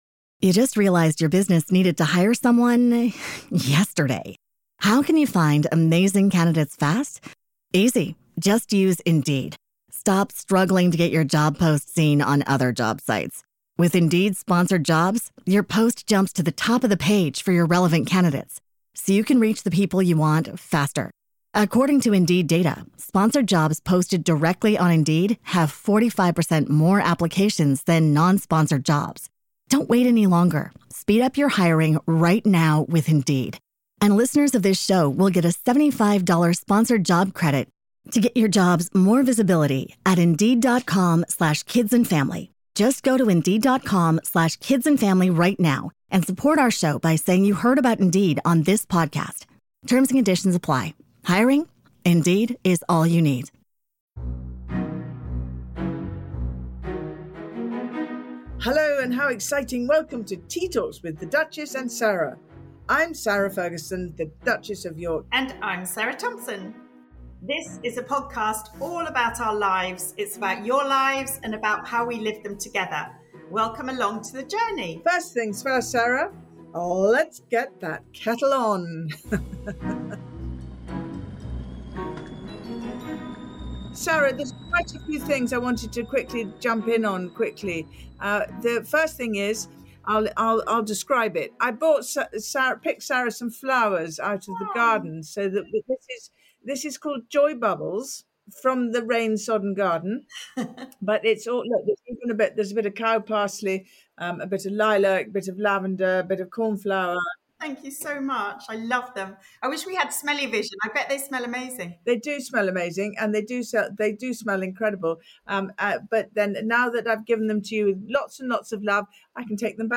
In between laughter and giggles, the hosts also chat about the importance of hobbies, while divulging some of their own secret pastimes…